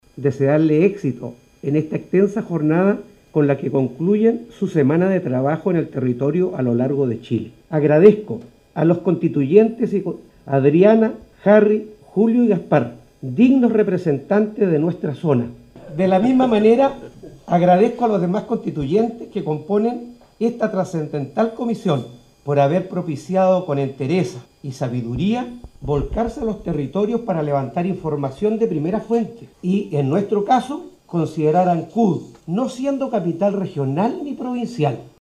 Acto seguido el alcalde de Ancud, Carlos Gómez, dio la bienvenida a los constituyentes a la comuna y a la isla grande.